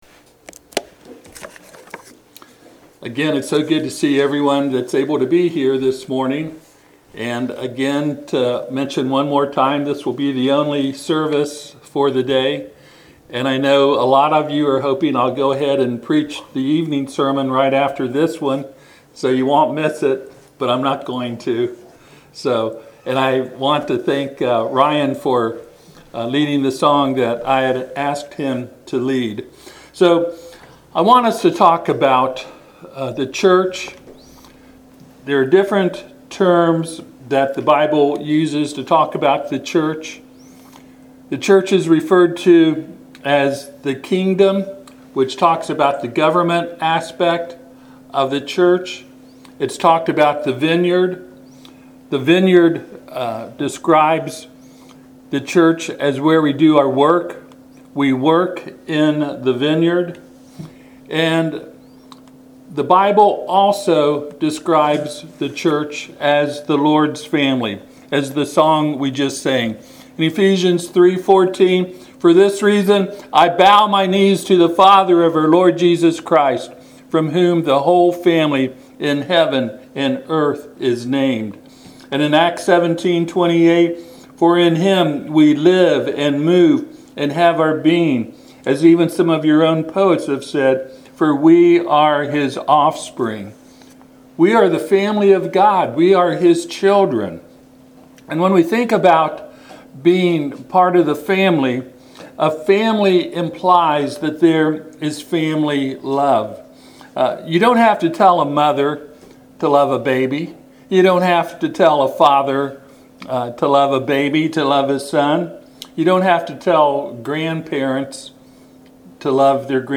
Passage: Ephesians 3:13-21 Service Type: Sunday AM